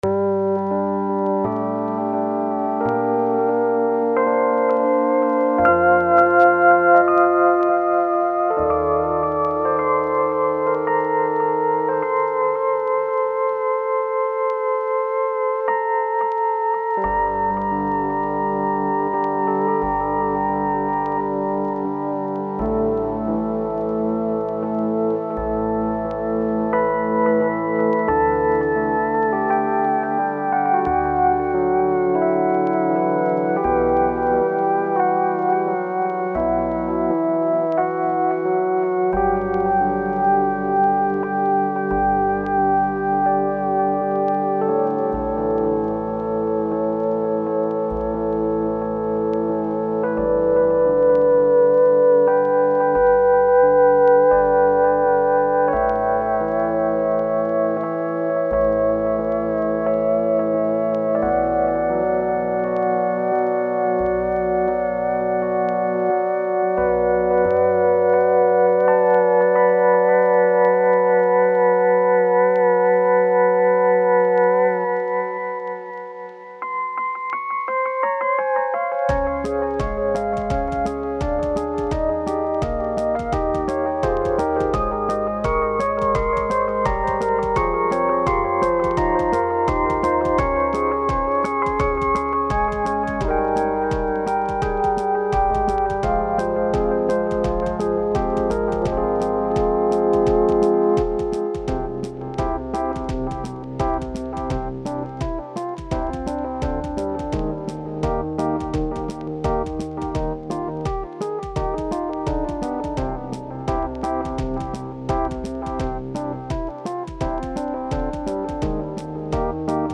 done by ripping the midis and doing things to them